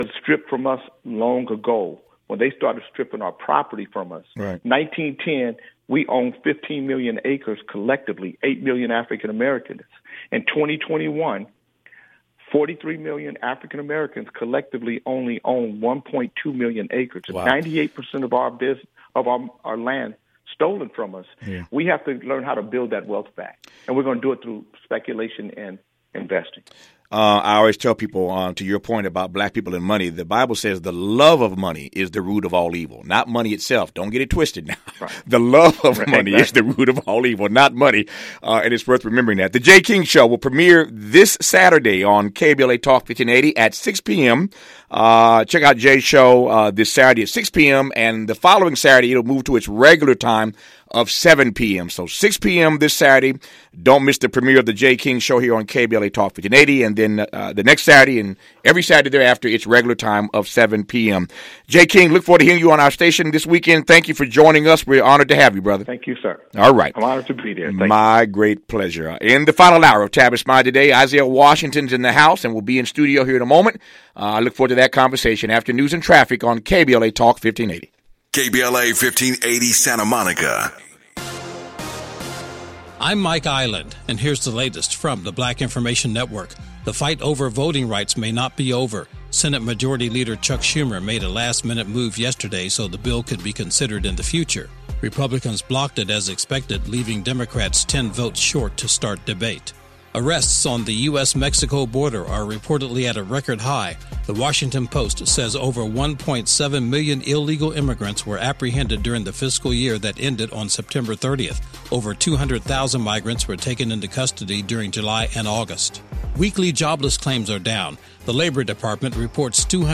During the segment on black talk radio station KBLA in Los Angeles, Isaiah Washington discloses that Ellen Pompeo took money under the table to the tune of 5 million not to disclose how toxic TR Knight was on the show which could’ve saved Isaiah Washington’s career at the time.